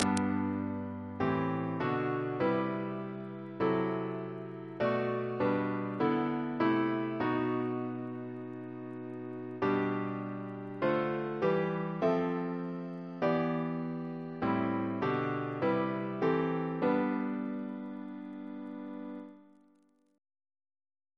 CCP: Chant sampler
Double chant in G Composer: Alec Wyton (1921-2007), Organist of St. John the Divine Reference psalters: ACP: 333